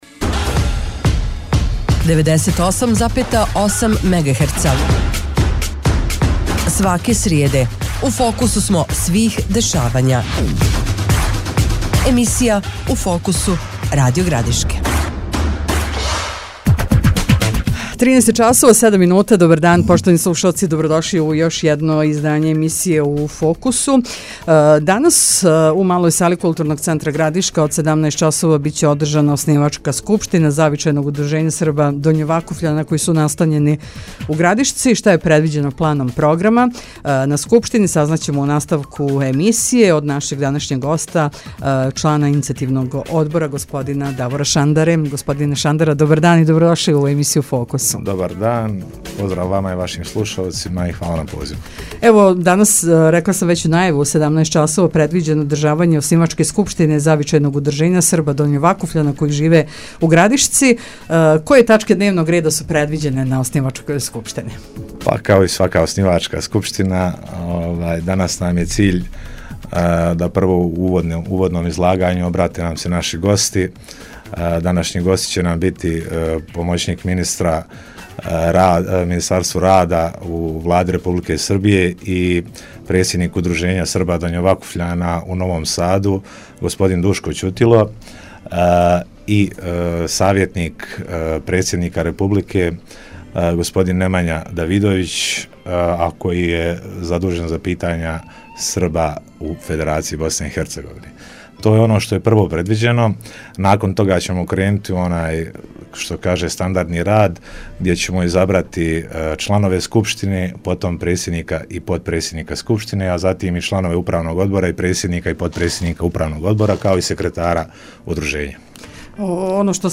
Гост емисије „У фокусу“ Радио Градишке